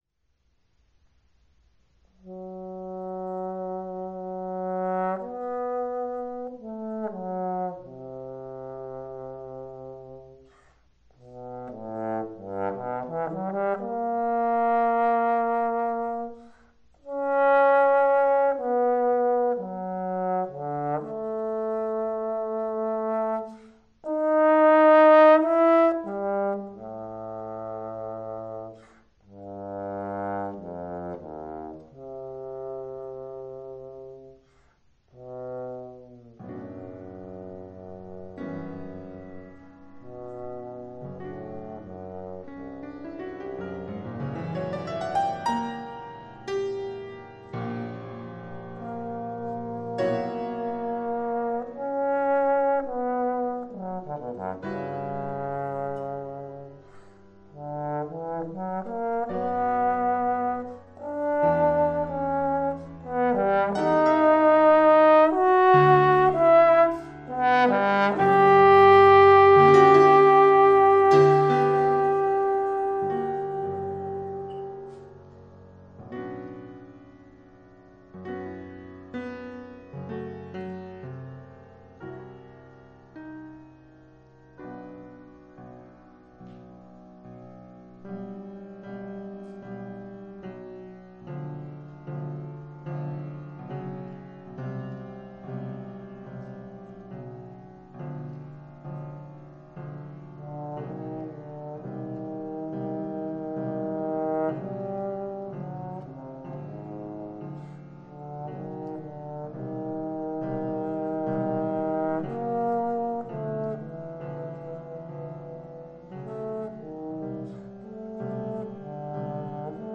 bass trombone and piano